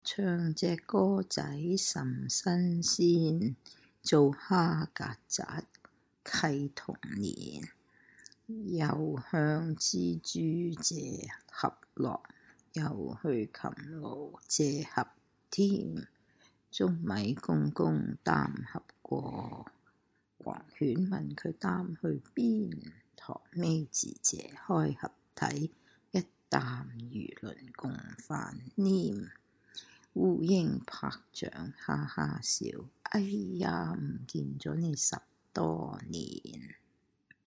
Songs, Ditties, Children’s Rhymes:
You may come across an older woman reciting these Cantonese ditties to a young child:
Here is an audial rendition of the last song: